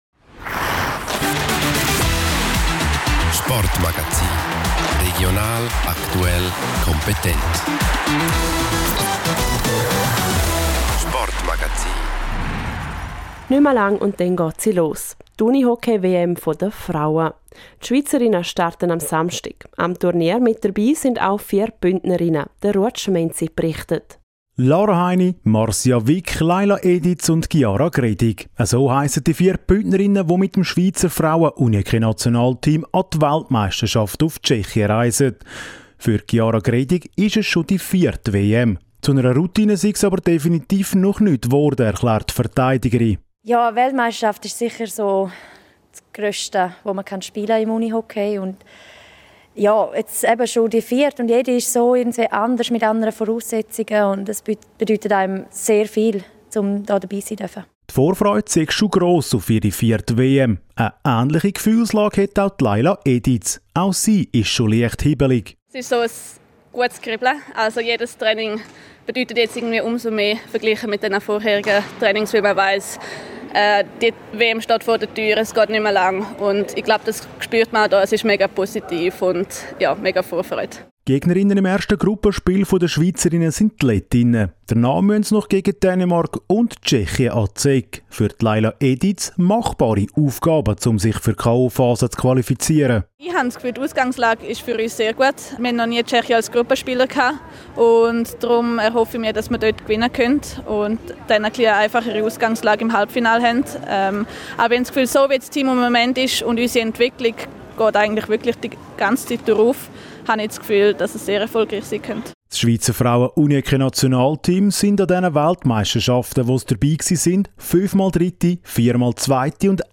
Im Schweizer Nationalteam sind auch vier Bündnerinnen. Sie werfen im Interview einen Blick auf die kommenden Spiele.